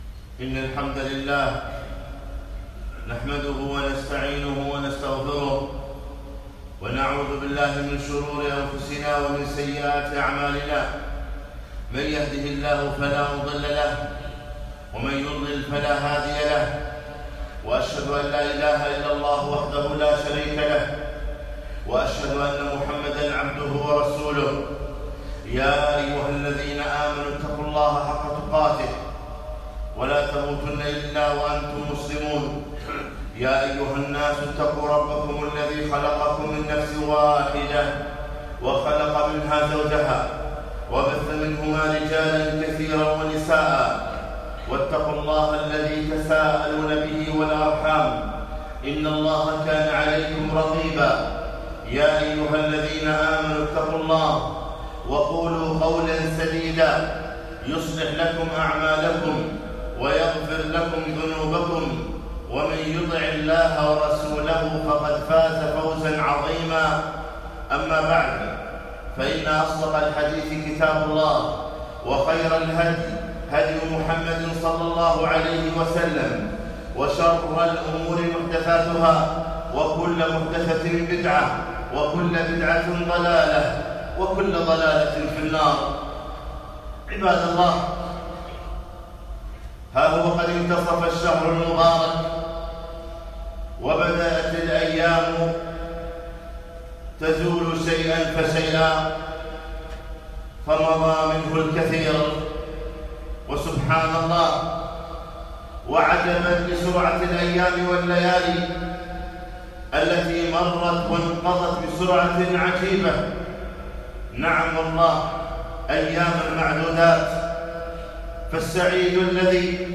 الجمعة 16 رمضان 1439 بمسجد عطارد بن حاجب الفروانية الكويت